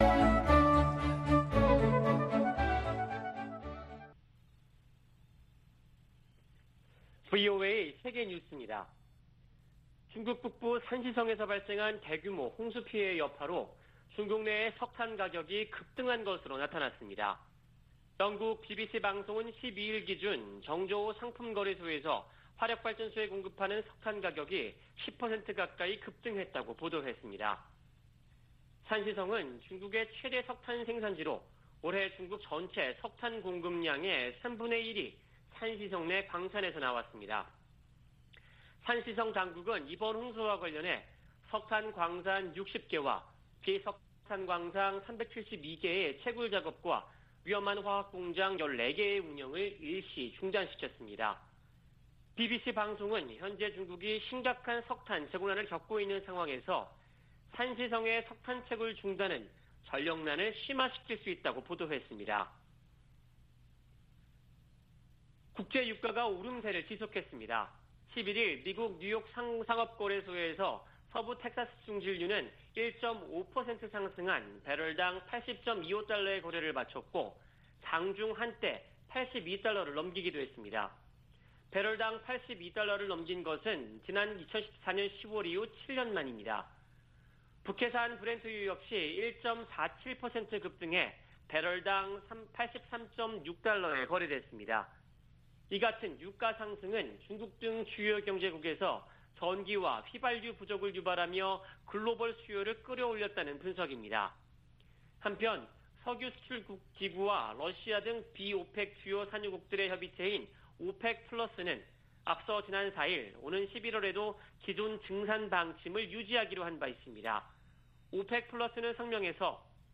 VOA 한국어 아침 뉴스 프로그램 '워싱턴 뉴스 광장' 2021년 10월 13일 방송입니다. 김정은 북한 국무위원장은 미국과 한국이 주적이 아니라면서도 핵무력 증강 지속 의지를 확인했습니다. 미국 정부의 대북정책을 지지하는 미국인이 작년보다 감소한 조사 결과가 나왔습니다. 옥스포드 사전에 '오빠' 등 한국어 단어 26개가 추가됐습니다.